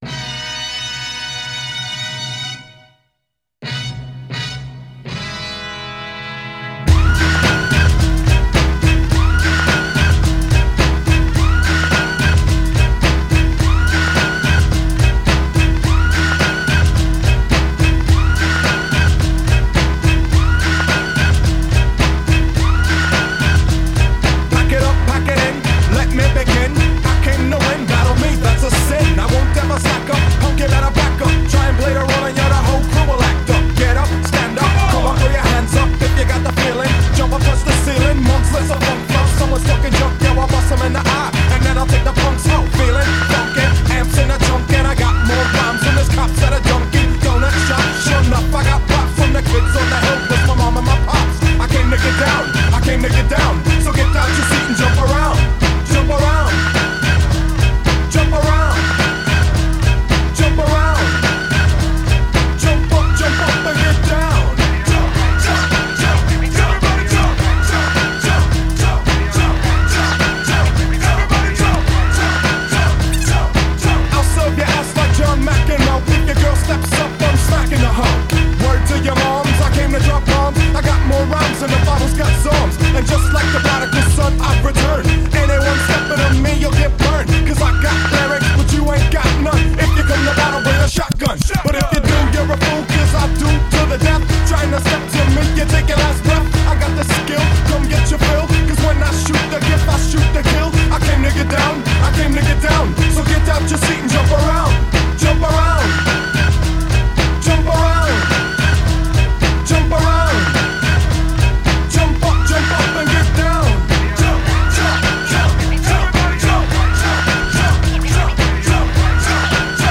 HipHop 90er